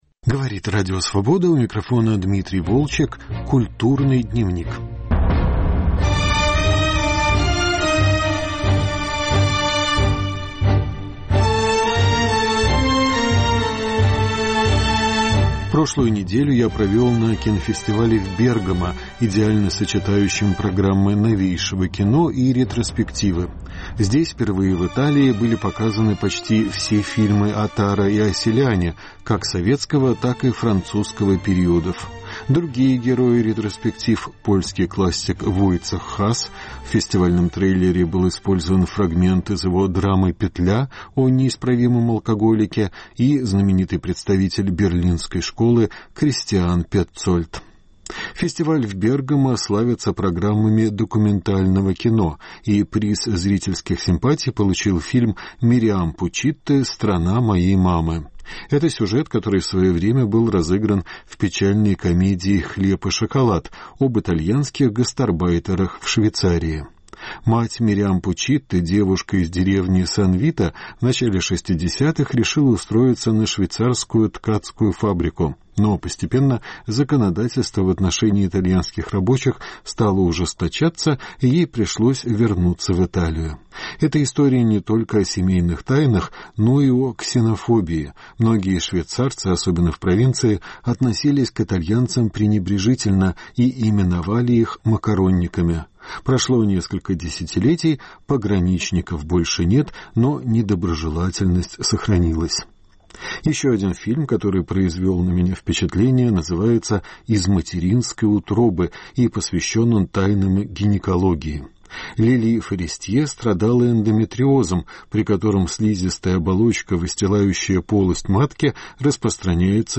Разговоры на фестивалях в Бергамо и Праге